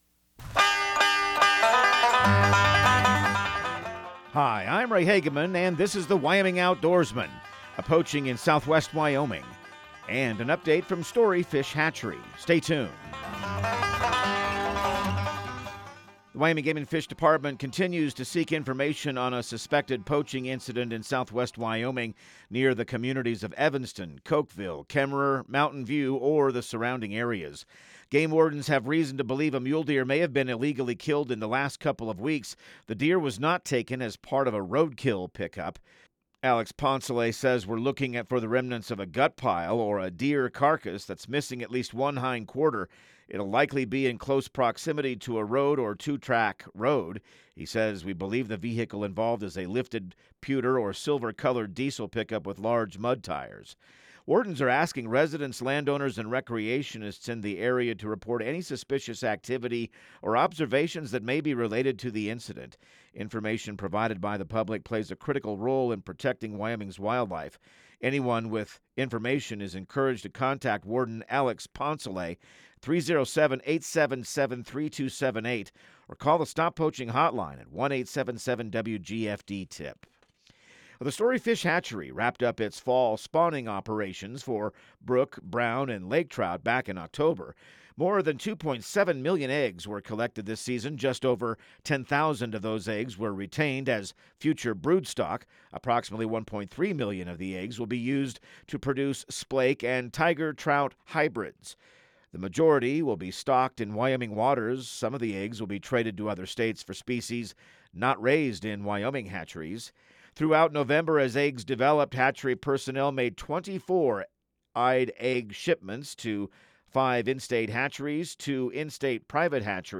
Radio news | Week of February 2